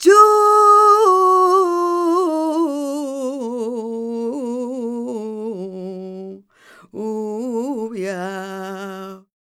46b06voc-f#.aif